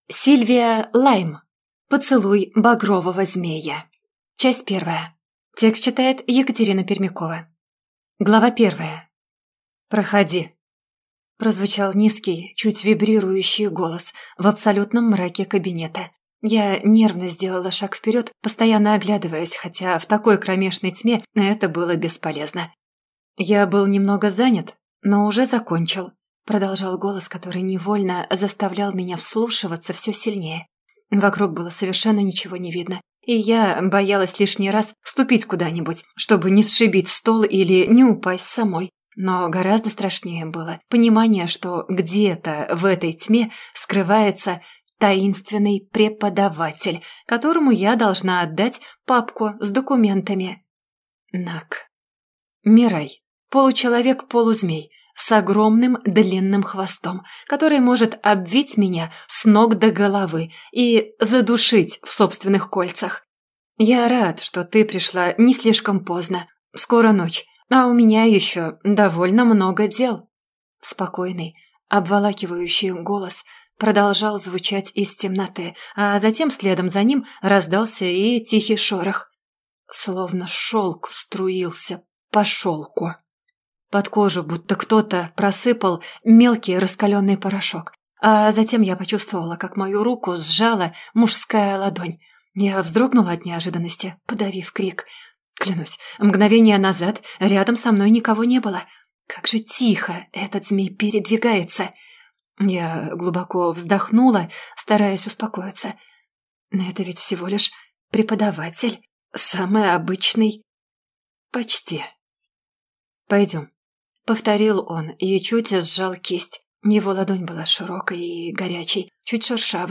Аудиокнига Поцелуй багрового змея. Часть 1 | Библиотека аудиокниг